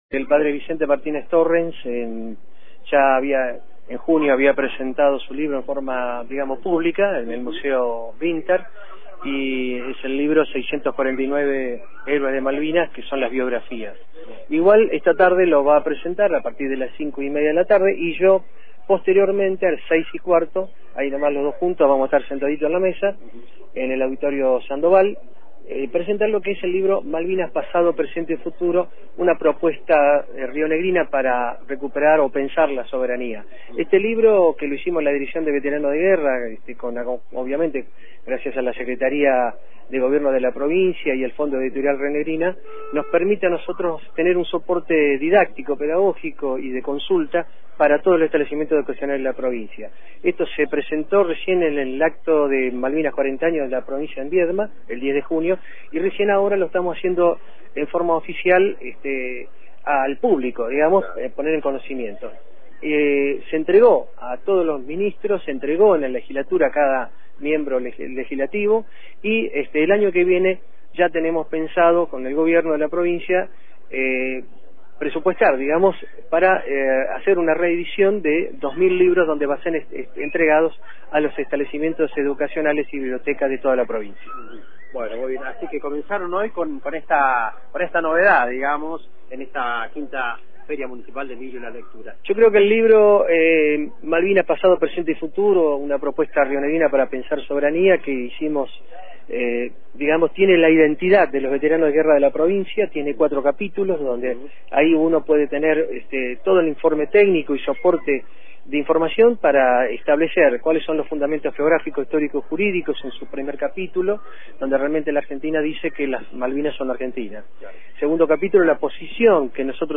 Entre los stands, nos encontramos con referentes de la Asociación de Veteranos de Malvinas, que están mostrando el libro de relatos del capellán Vicente Torrens.